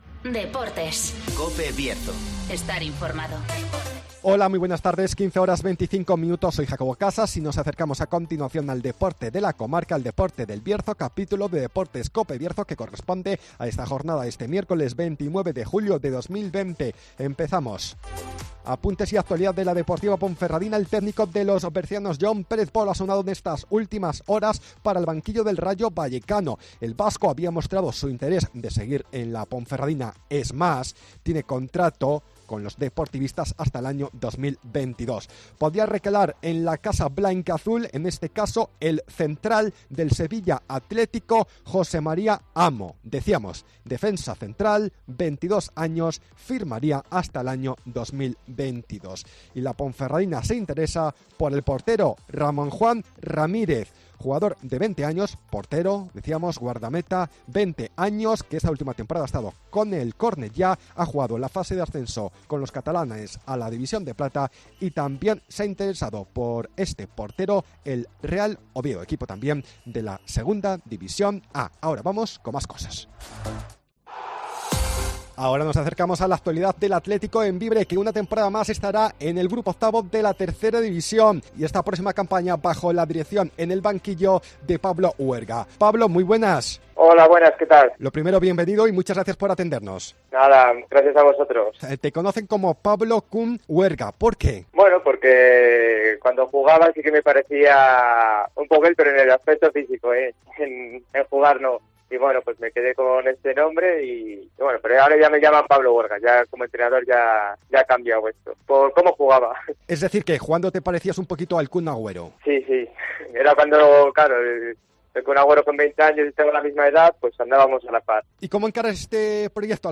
-Entrevista